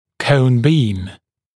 [kəun biːm][коун би:м]конусный луч